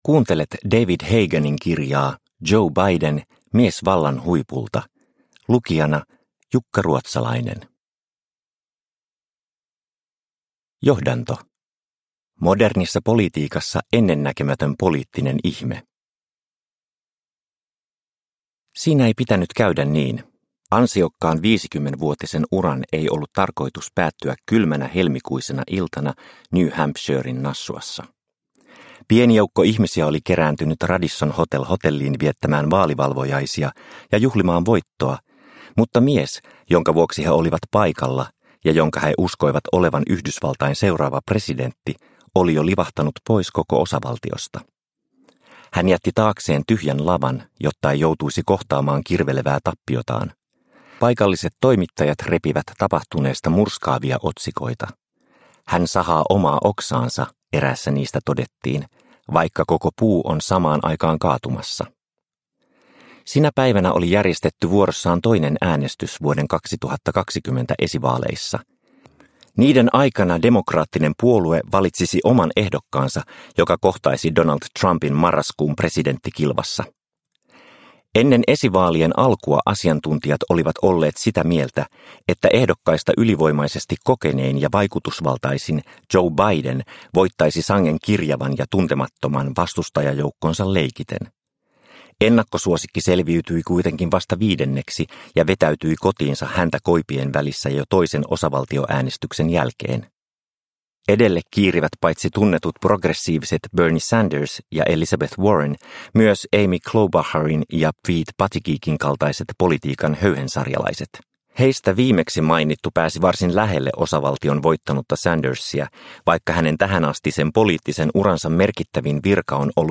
Joe Biden - Mies vallan huipulta – Ljudbok – Laddas ner